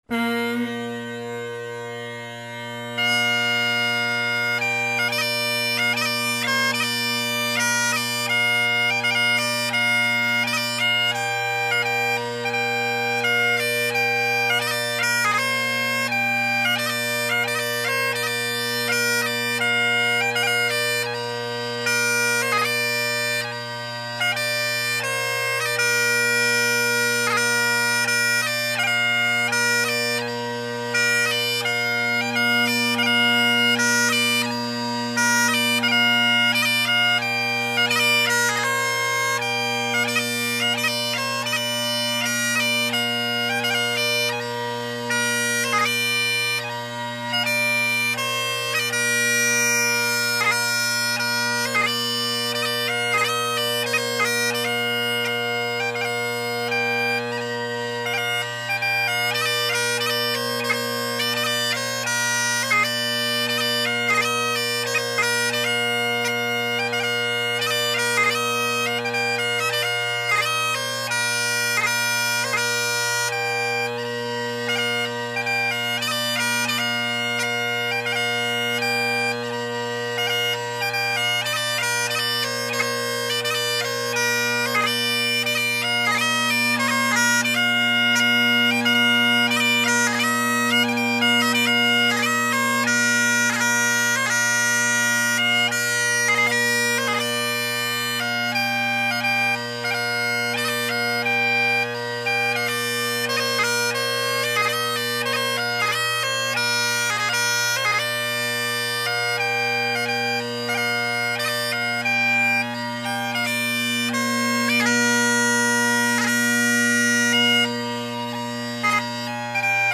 Great Highland Bagpipe Solo
First some 4/4’s: